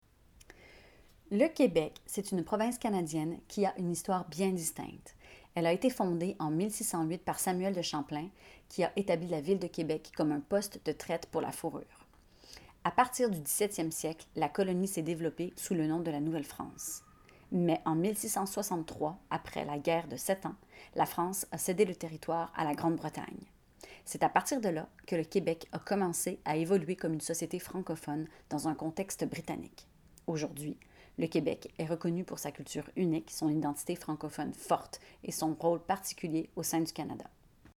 12 - 94 ans - Mezzo-soprano